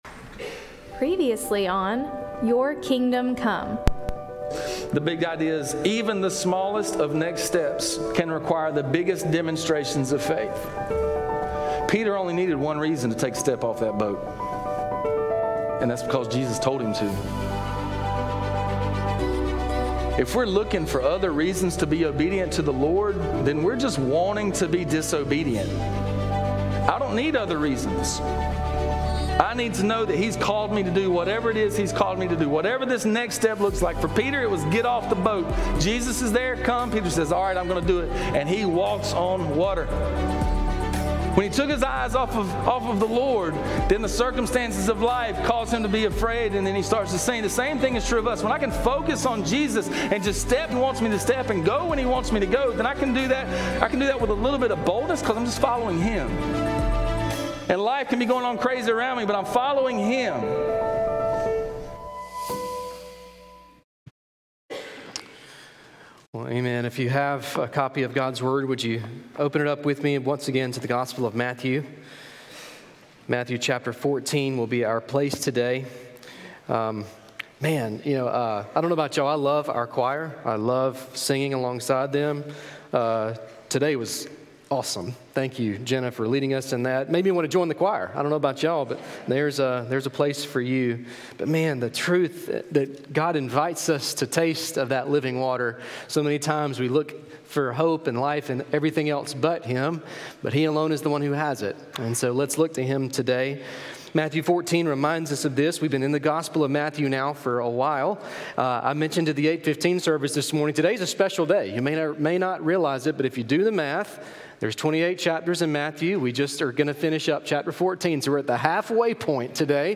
Message: "Why the Cross?"